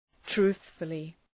Προφορά
{‘tru:ɵfəlı}